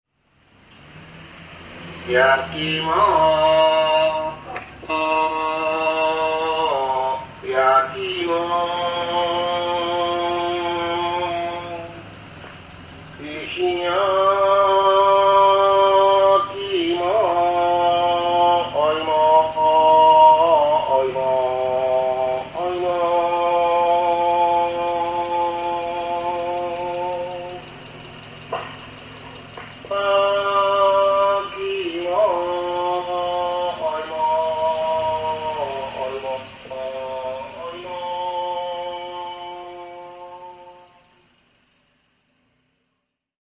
the song of the yaki imo (baked sweet potato) truck heading into my neighborhood.